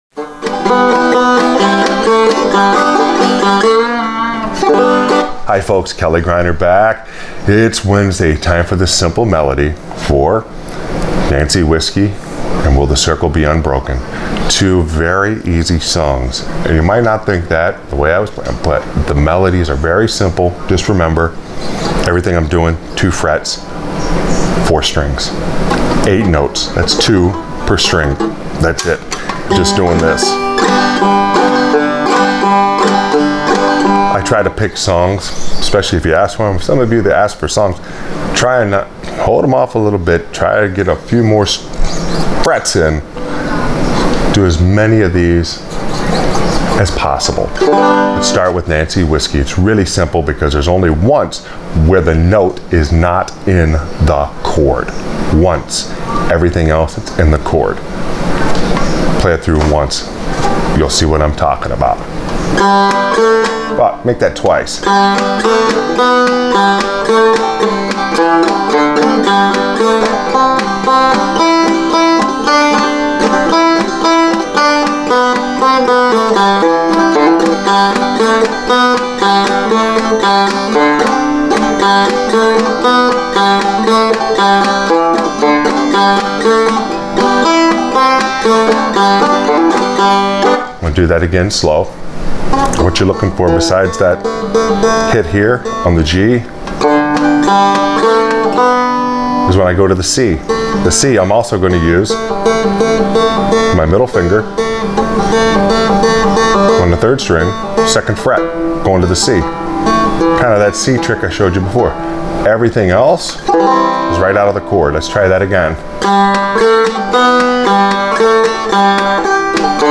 Simple Melody for Nancy Whiskey and Will The Circle Be Unbroken on Frailing Banjo